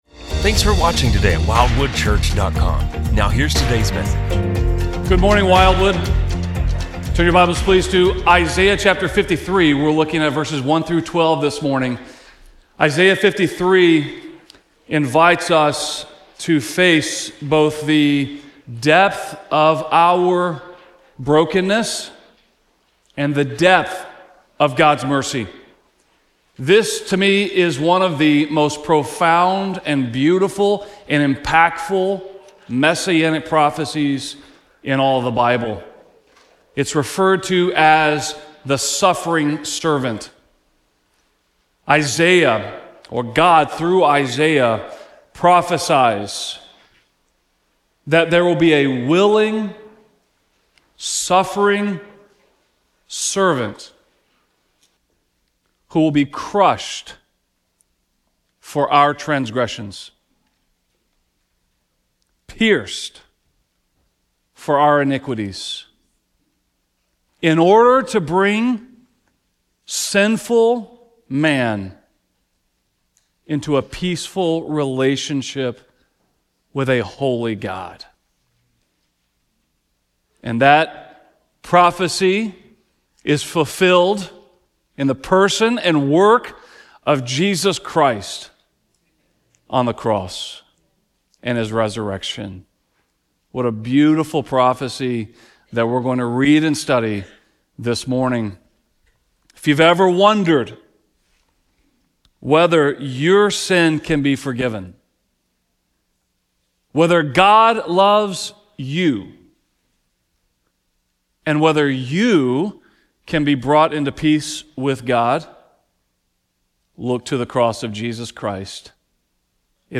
Isaiah 53:1-12 shows us the Suffering Servant who steps into our griefs, carries our sorrows, and willingly takes the punishment our sins deserve. In this message we see how the cross is not just a tragic event, but the planned, willing, and effective sacrifice that brings us peace with God. This sermon calls wandering, weary sinners to look to Christ alone for forgiveness, healing, and everlasting peace.